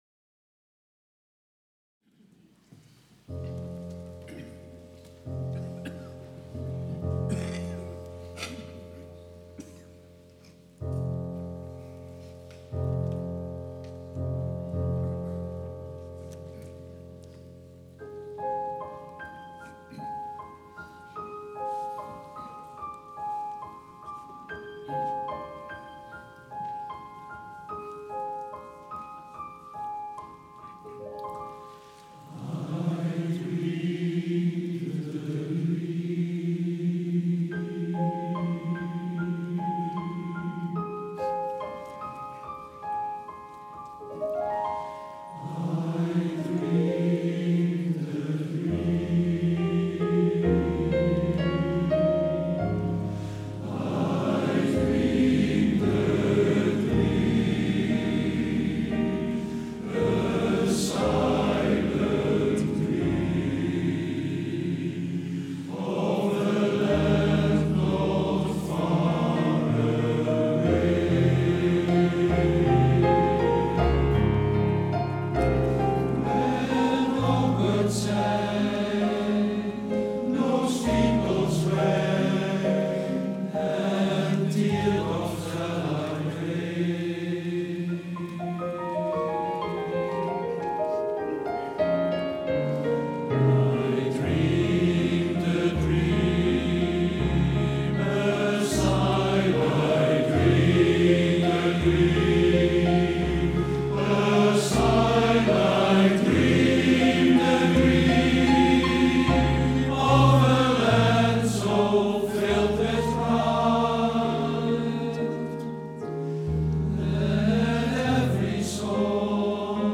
Kijk en Luister – Horster Mannenkoor
Concert in de Haandert op 5 november 2023